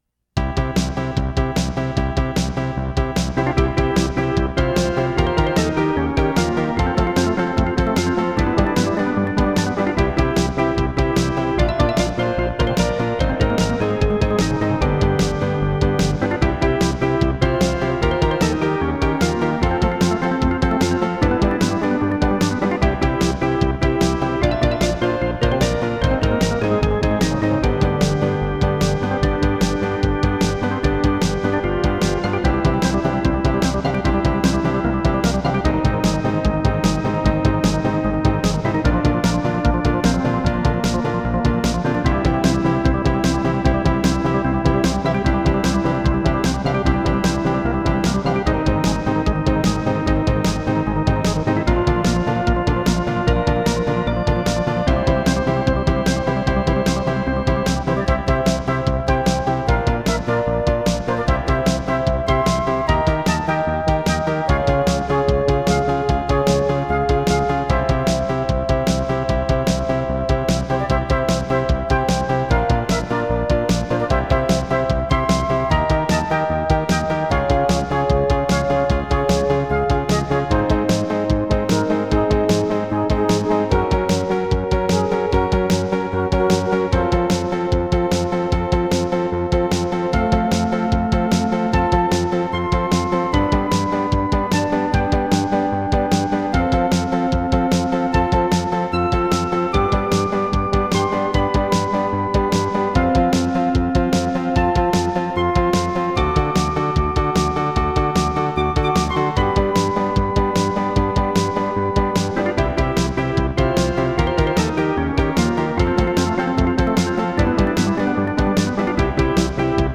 As recorded from the original Roland MT-32 score